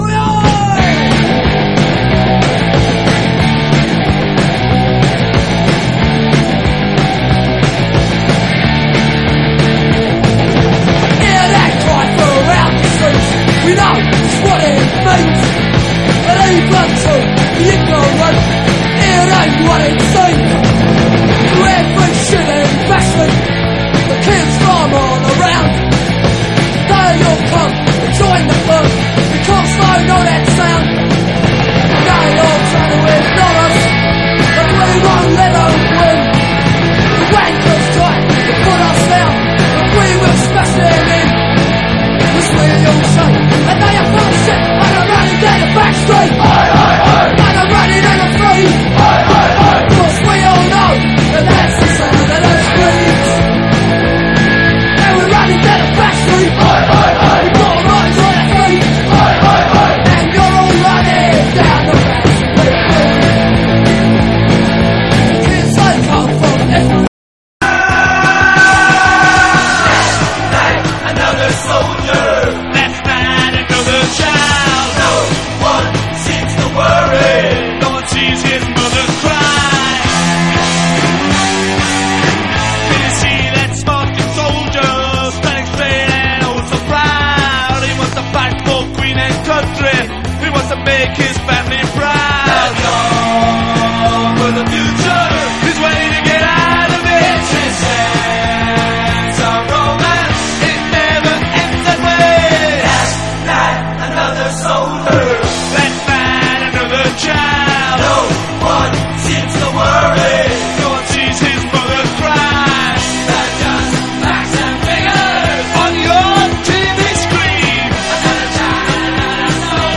ROCK / PUNK / 80'S～ / Oi! / STREET PUNK (UK)